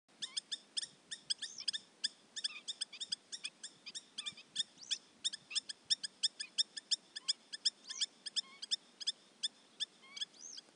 نام فارسی : خروس کولی سینه سیاه
نام انگلیسی :Spur-winged Lapwing
پرندگان پر سر و صدایی هستند که به صورت گروهی به سر می برند